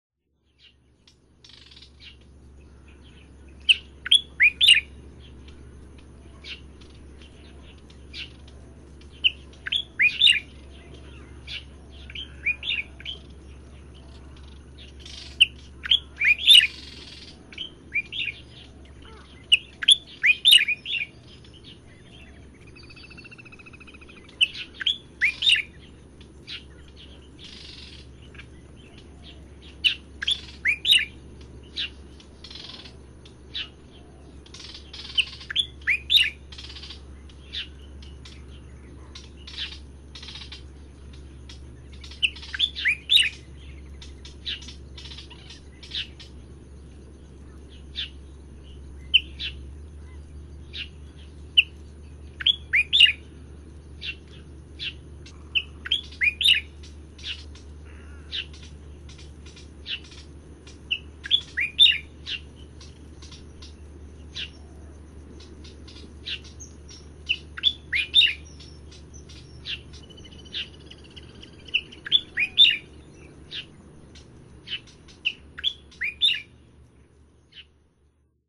從清晨到黃昏的六段充滿鳥聲的自然錄音，搭配上27種鳥聲，最後以夜晚的貓頭鷹聲音做結尾，是最值得珍藏的自然聲音CD。